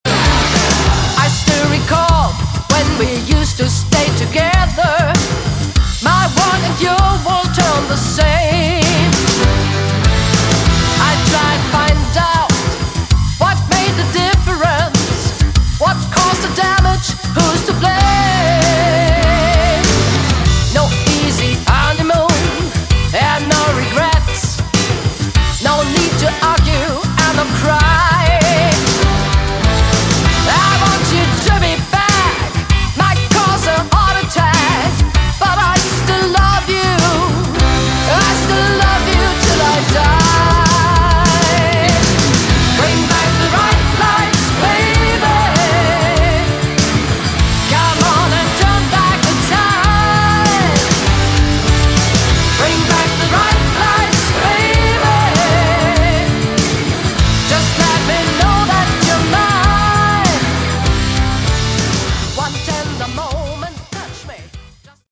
Category: Hard Rock
lead vocals
guitars
keyboards
bass
drums